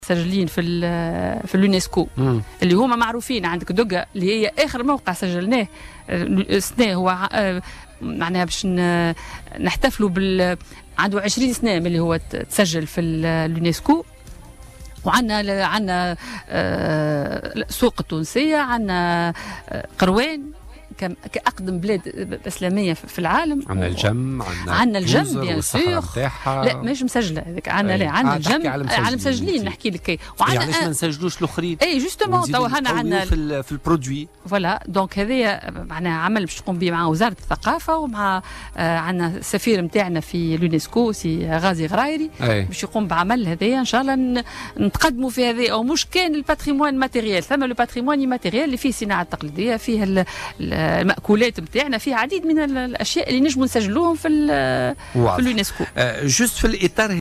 وقالت ضيفة بوليتيكا على "الجوهرة أف أم" إنه تم بعدُ ادراج 8 مواقع بهذه المنظمة الأممية إلا أنه لم يتم تسجيل أي موقع جديد منذ نحو 20 عاما.